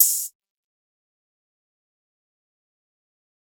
TS - OPEN HAT (2).wav